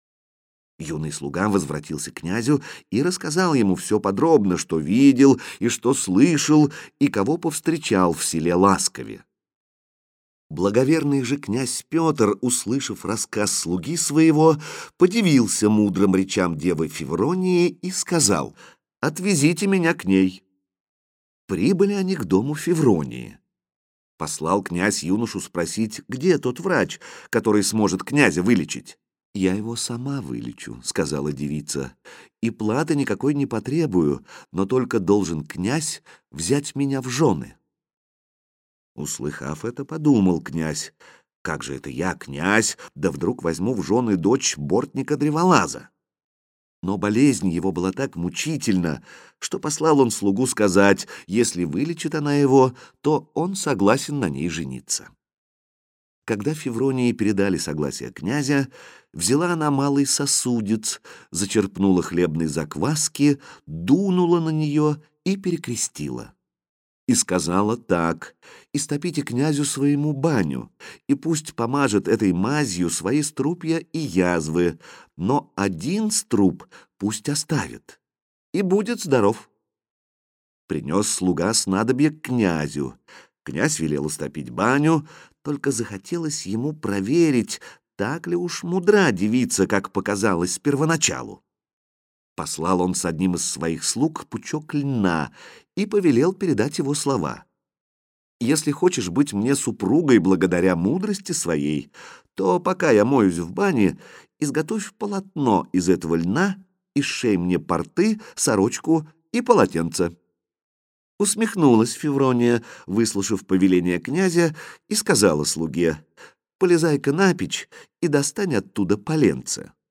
Повесть о Петре и Февронии Муромских - аудио повесть - слушать онлайн